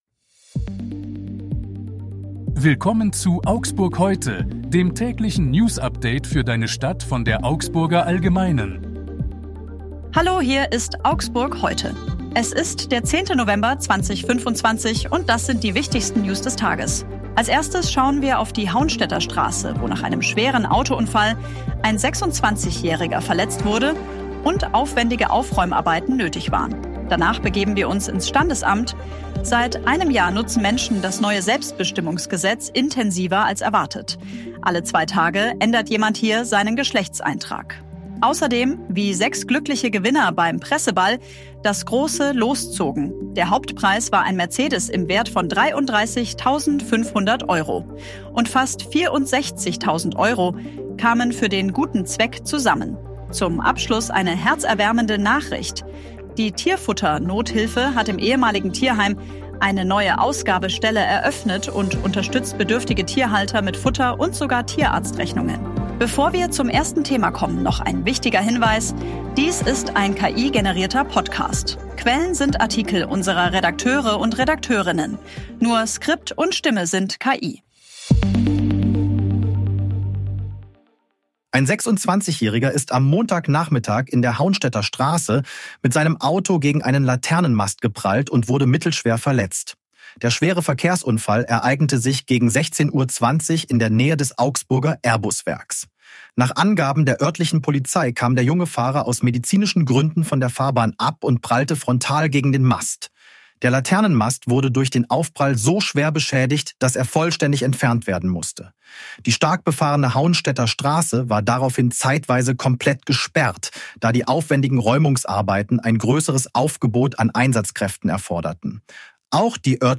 Hier ist das tägliche Newsupdate für deine Stadt.
Nur Skript und Stimme sind KI